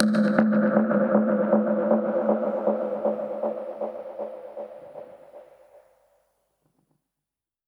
Index of /musicradar/dub-percussion-samples/125bpm
DPFX_PercHit_A_125-11.wav